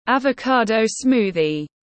Sinh tố bơ tiếng anh gọi là avocado smoothie, phiên âm tiếng anh đọc là /ævou’kɑ:dou ˈsmuːði/
Avocado smoothie /ævou’kɑ:dou ˈsmuːði/